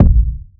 Kick - Polow.wav